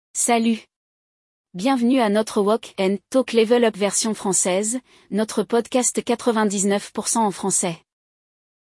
No episódio de hoje, um homem faz algumas perguntas complicadas para uma mulher, que não parece estar gostando muito da brincadeira.